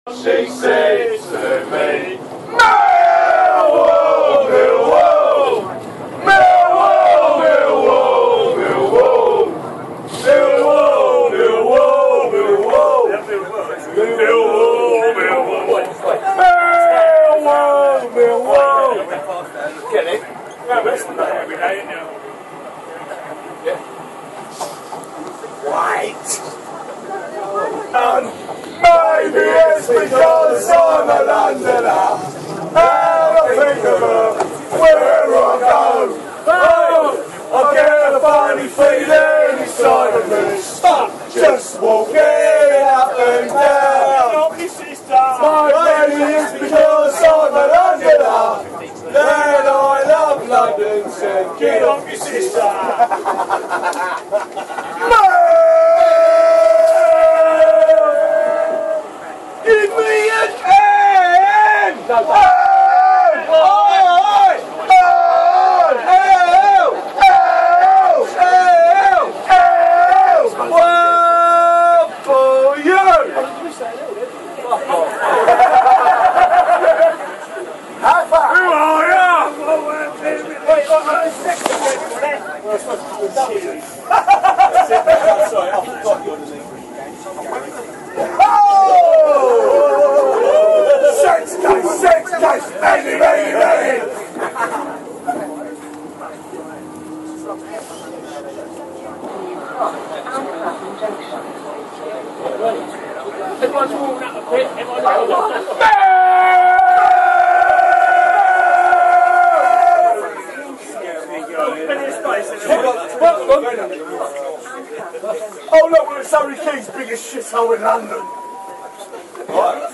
Millwall fans giving it large
Heard just before Saturday's match, on the East London Line.